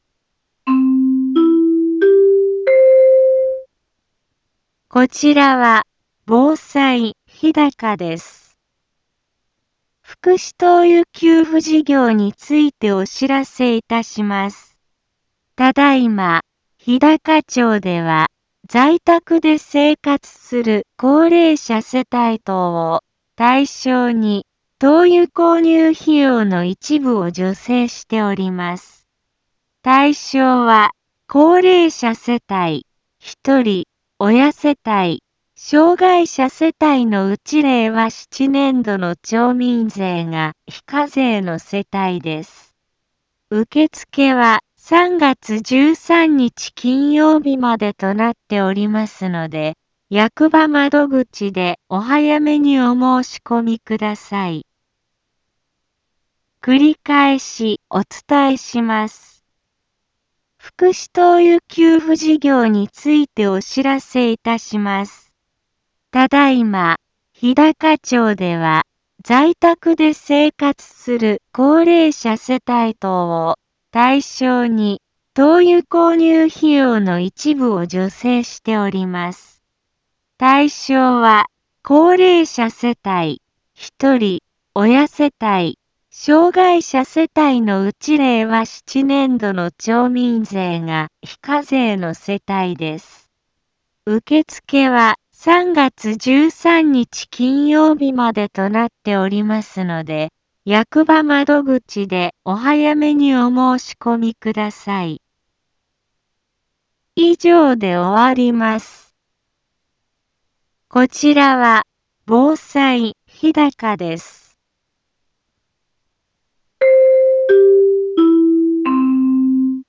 一般放送情報
Back Home 一般放送情報 音声放送 再生 一般放送情報 登録日時：2026-02-24 10:04:12 タイトル：福祉灯油給付事業のお知らせ インフォメーション： こちらは、防災日高です。 福祉灯油給付事業についてお知らせいたします。